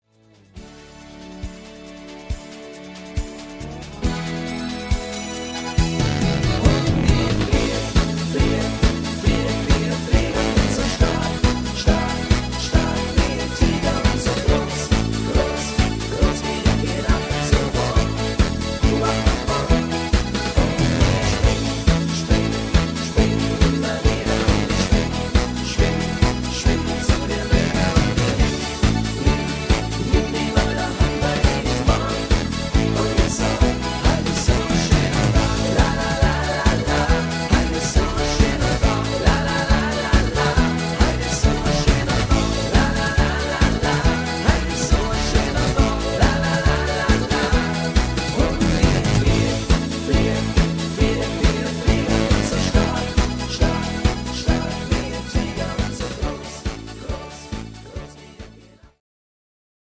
Wiesnmix